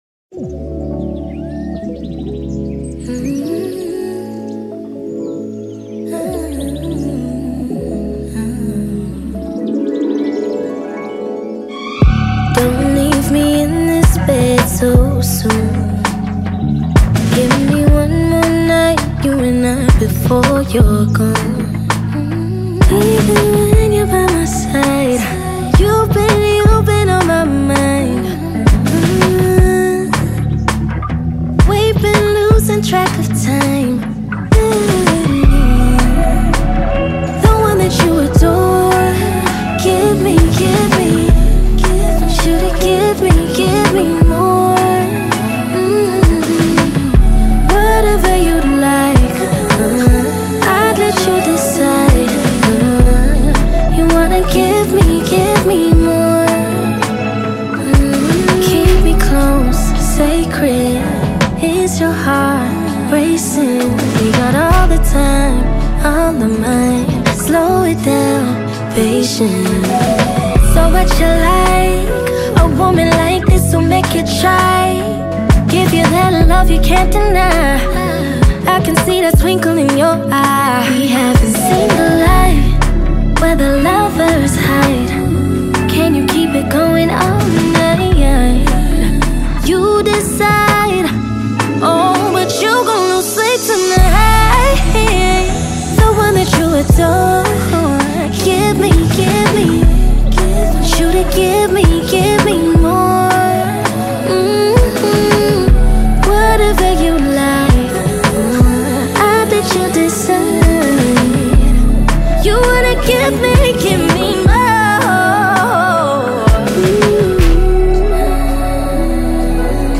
sultry R&B track
soulful vocals